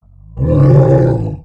MONSTER_Growl_Medium_09_mono.wav